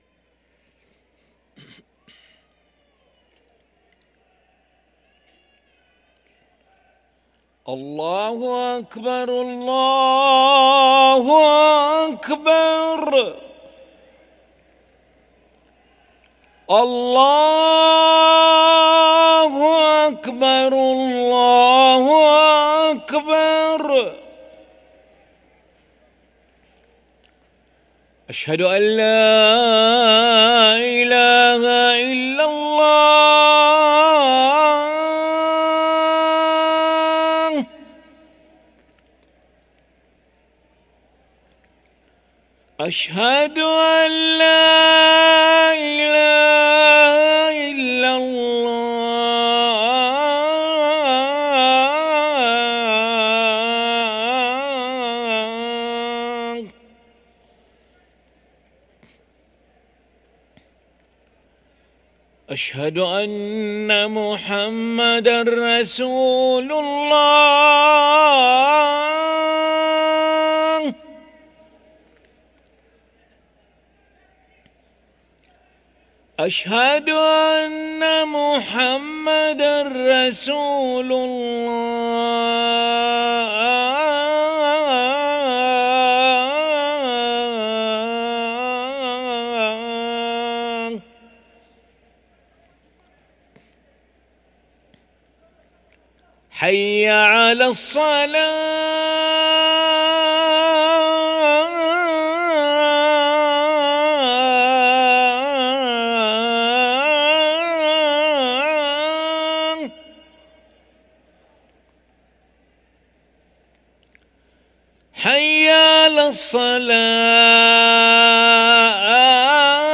أذان العشاء للمؤذن علي ملا الأحد 9 ربيع الأول 1445هـ > ١٤٤٥ 🕋 > ركن الأذان 🕋 > المزيد - تلاوات الحرمين